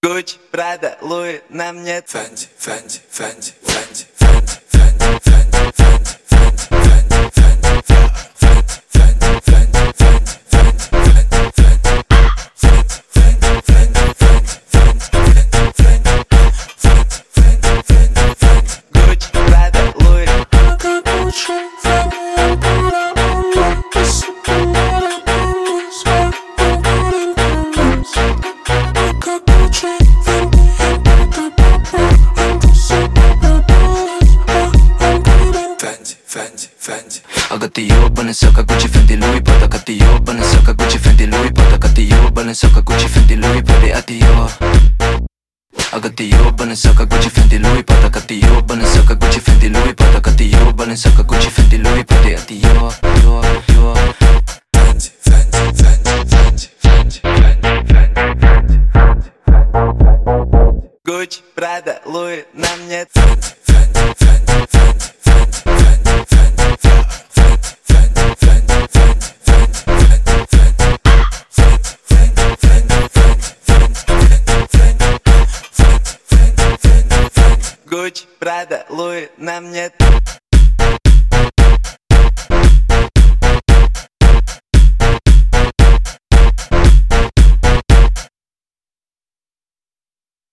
Поп музыка, Рэп